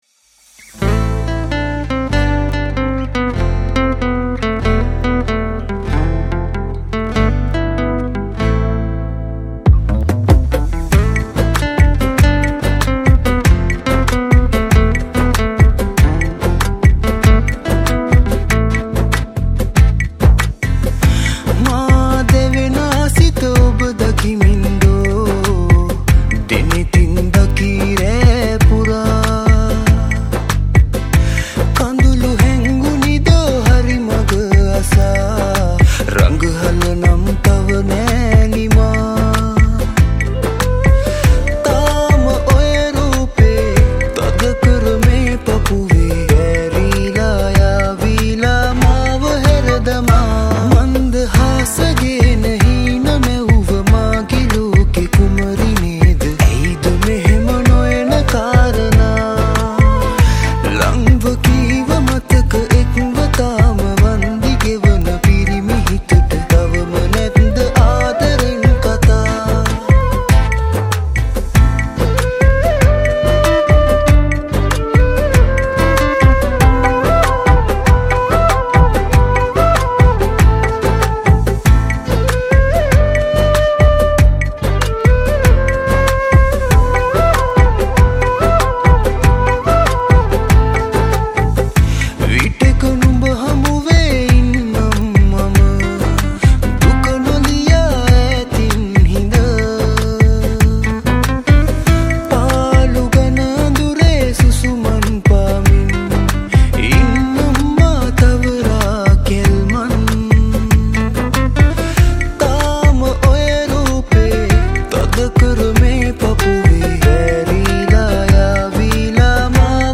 Guitars
Flute